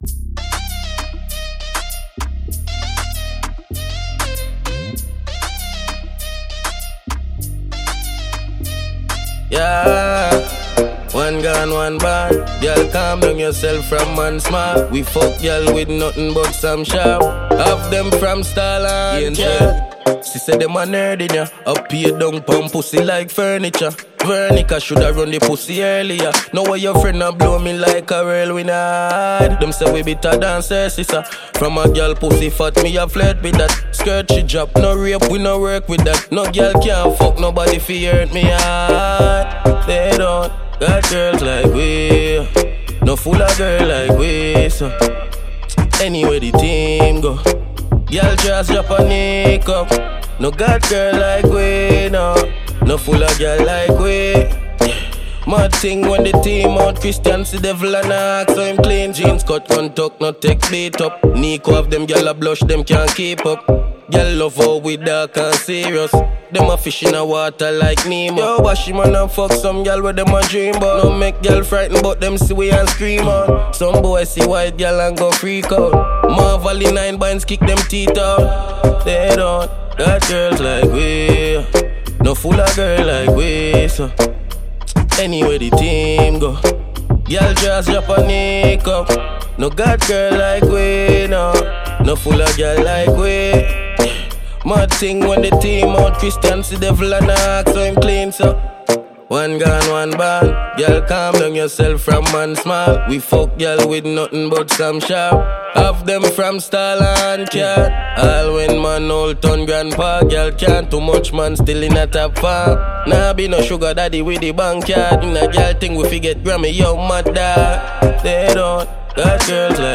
Dancehall Reggae
Jamaican dancehall musician